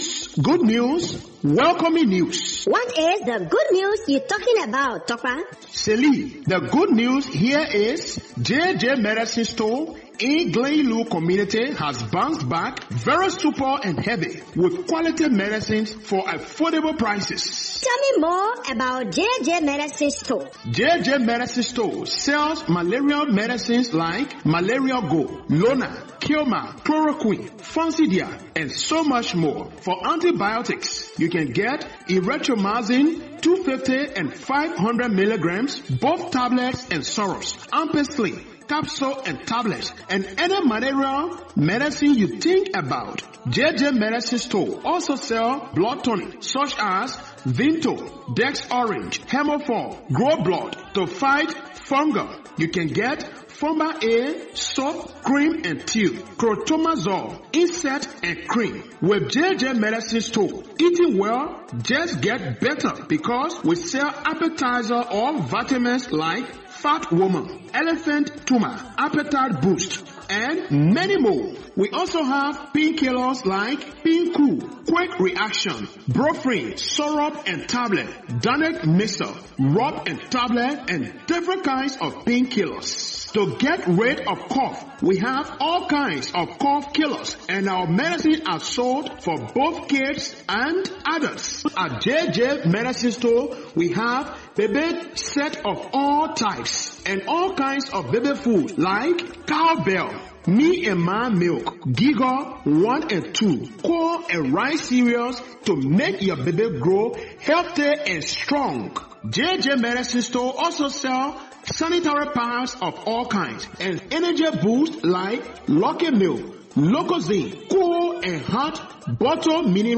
Brand: JJ Medicine Store Advert: Pharmacy